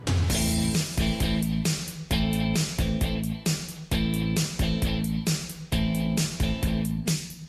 отрывок песни